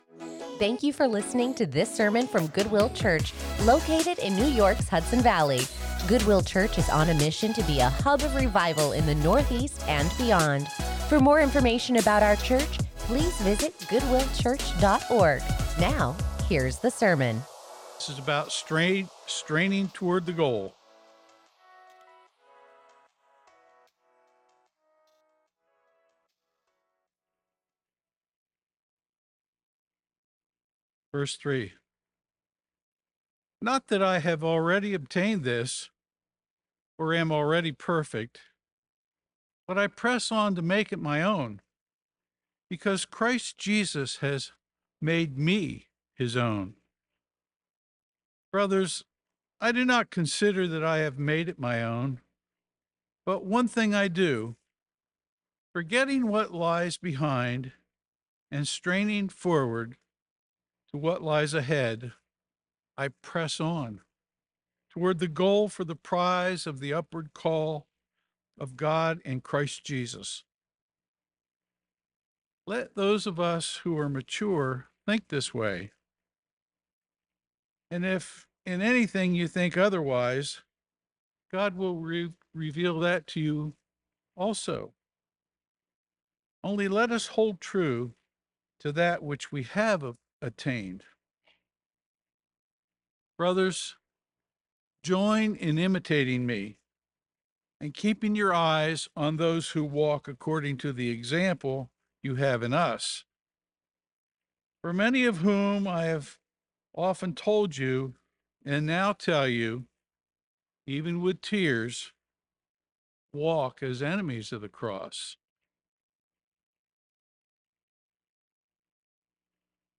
Join us in studying God's Word as we take a break from the sermon series, “Start Today, Don't Quit” | Philippians 3:12-20